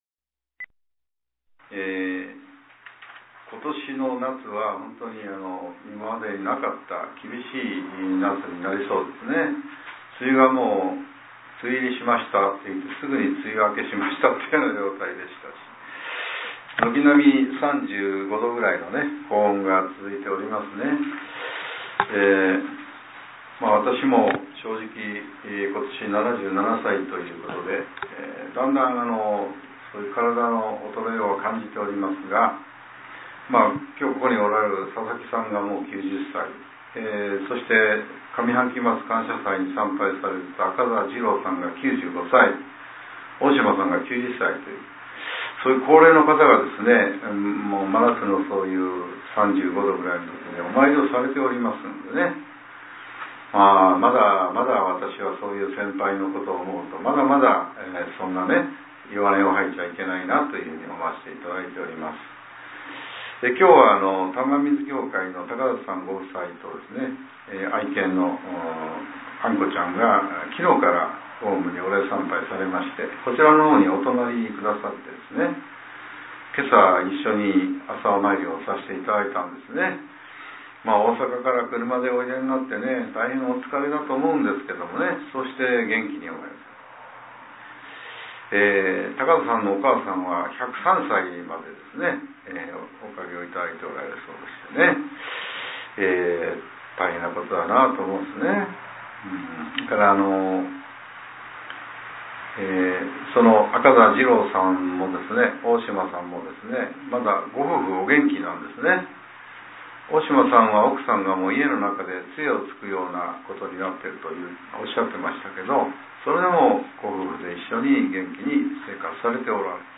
令和７年７月１日（朝）のお話が、音声ブログとして更新させれています。